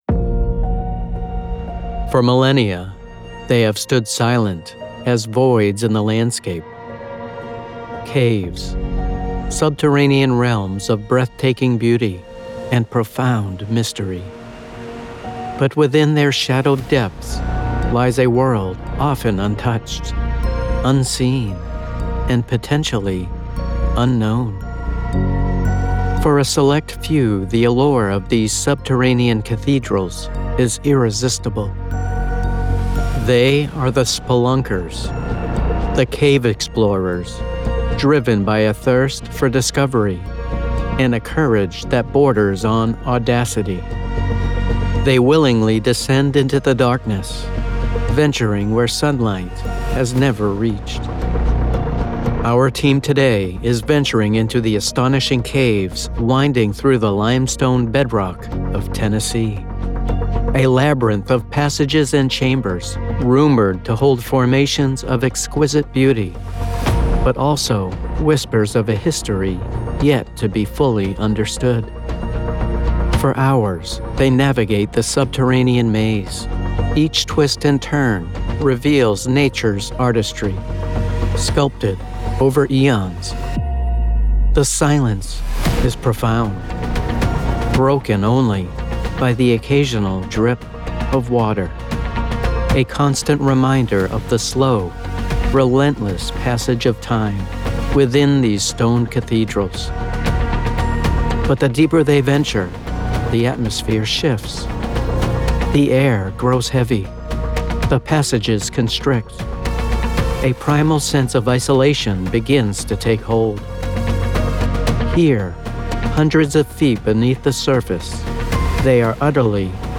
Male
I have a conversational tone that is natural, believable, and friendly.
Documentary
Youtube Style Documentary
0812YOUTUBE_STYLE_Mixdown__1_.mp3